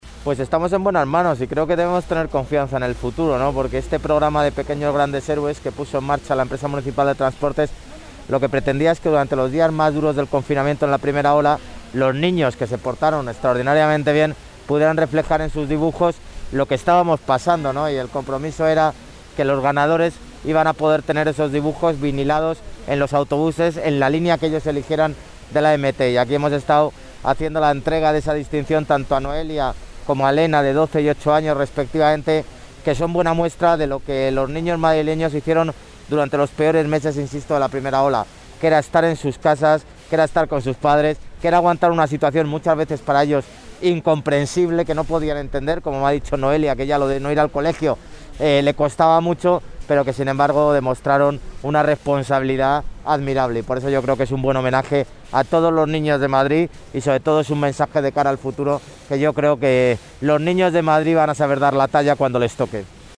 AUDIO-ALCALDE-SOBRE-CONCURSO-INFANTIL-PINTURA-EMT.mp3